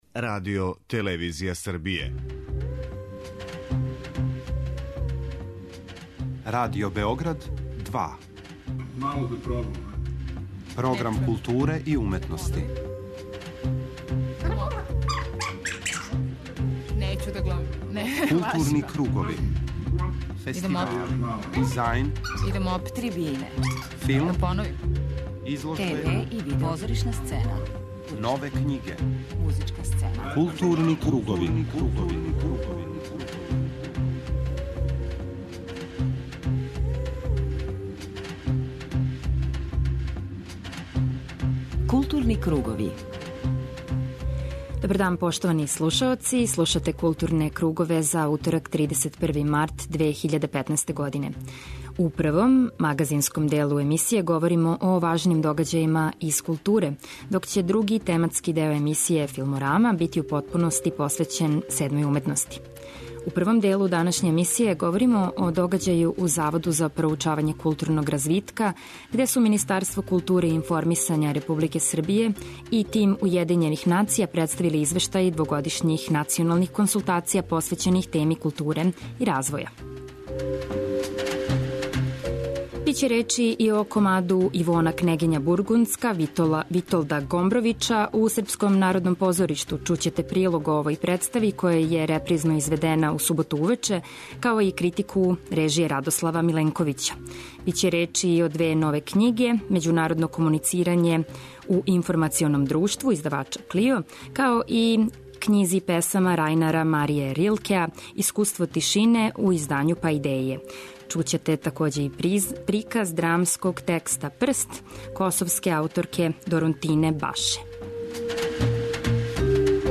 У данашњој емисији слушаћете разговоре са лауреатима фестивала и њихове утиске о фестивалу и овогодишњем програму.